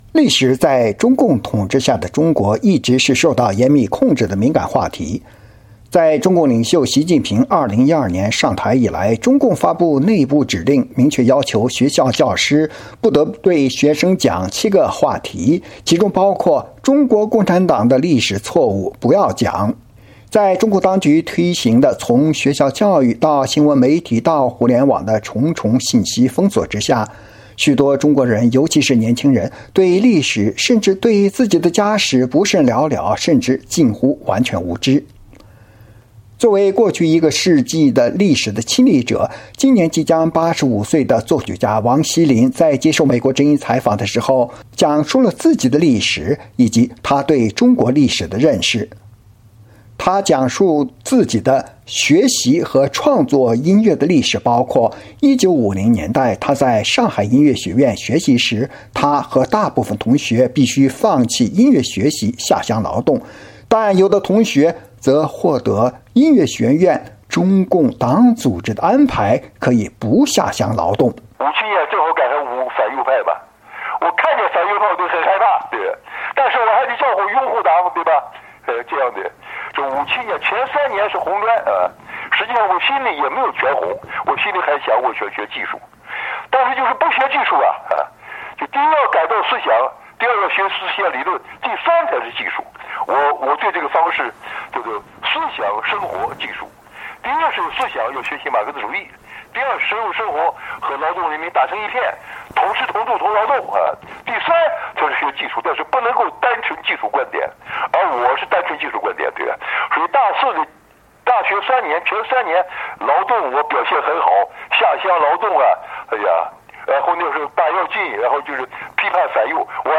作为过去一个世纪的历史亲历者，今年85岁的作曲家王西麟在接受美国之音采访时讲述了自己的历史，以及他对中国历史的认识。